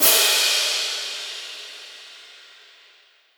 Crash 1.wav